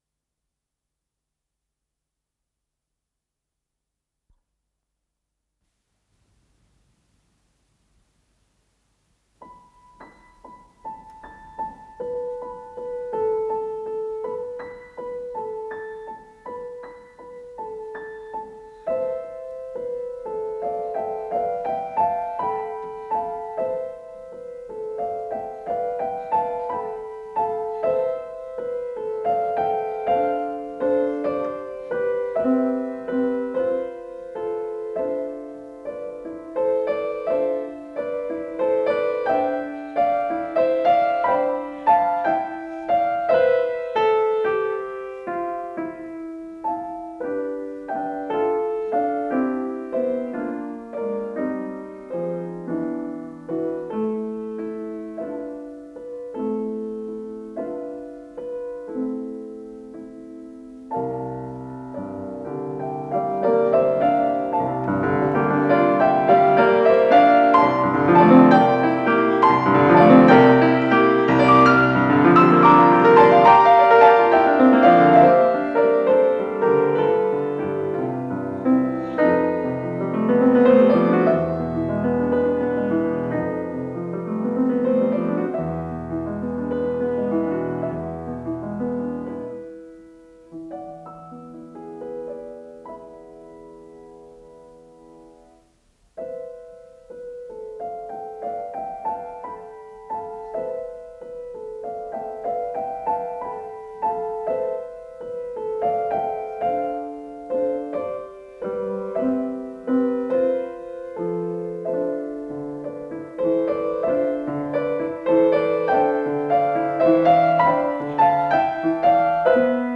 с профессиональной магнитной ленты
ля мажор
фортепиано